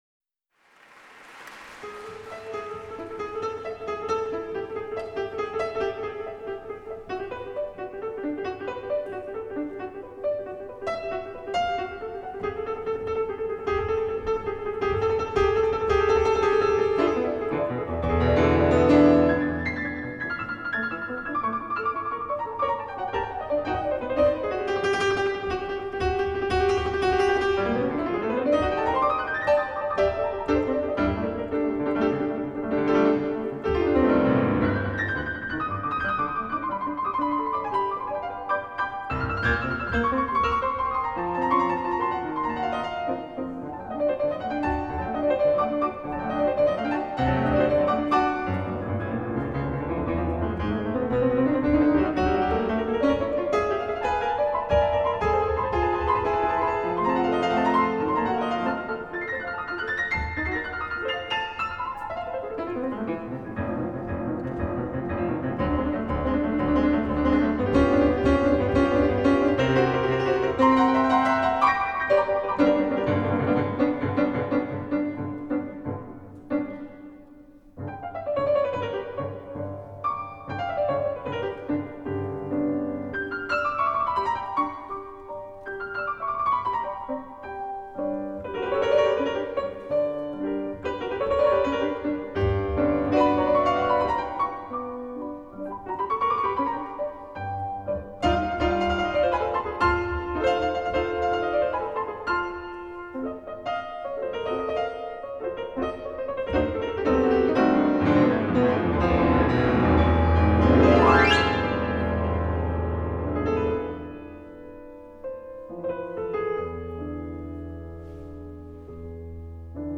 Giovanni Bottesini (1821 – 1889) - Grand Duo concertante für Kontrabass, Violine und Orchester
Personen Roma und Sinti Philharmoniker